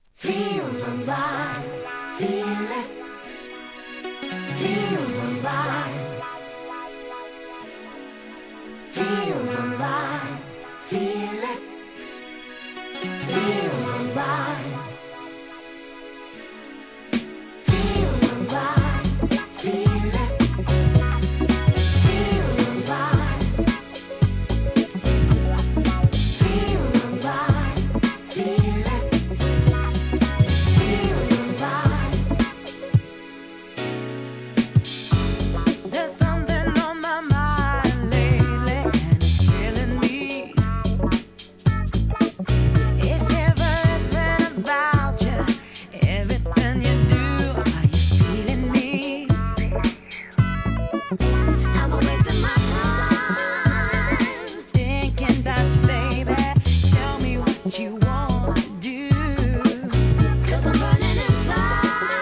HIP HOP/R&B
硬派なUK SOUL!!